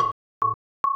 Listen to A2 then synthetic A2 then 1121 Hz plus 1090 Hz combination tone